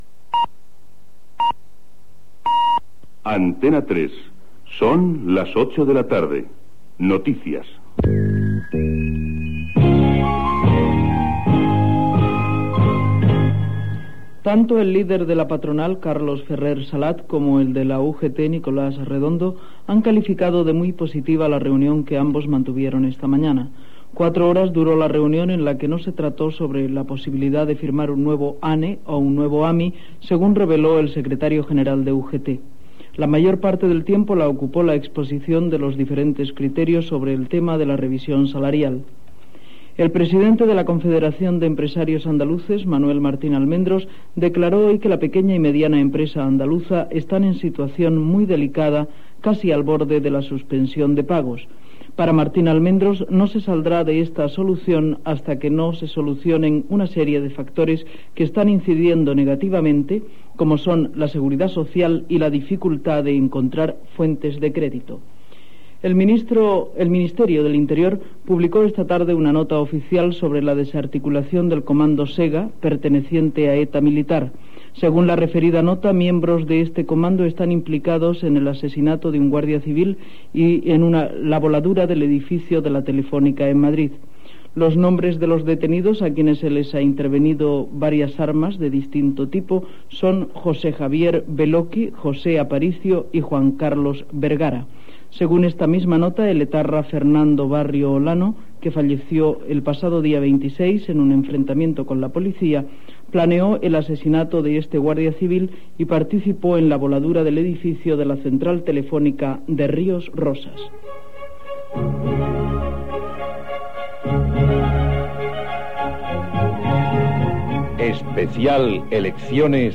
Informatiu
Primer dia d'emissió d'Antena 3 de Barcelona des del Sonimag.